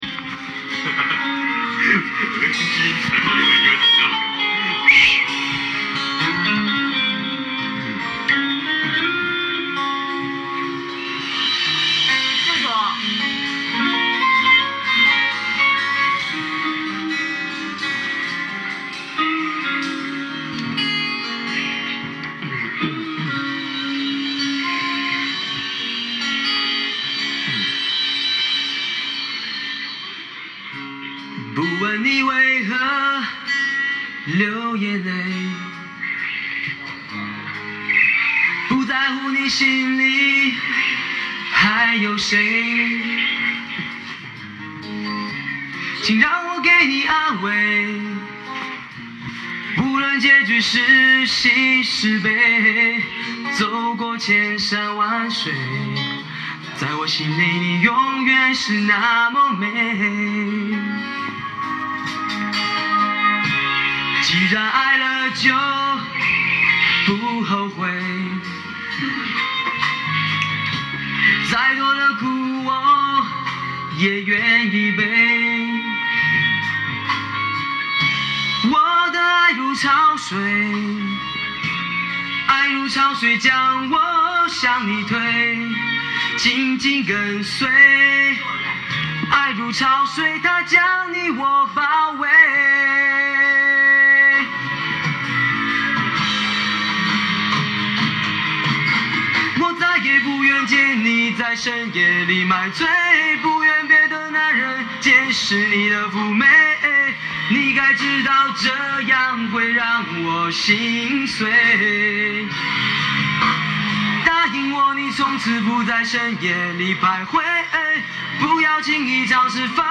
一直在清嗓超逊的{:5_145:}